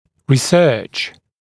[rɪ’sɜːʧ][ри’сё:ч]исследование, изыскание, научно-исследовательская работа; исследовать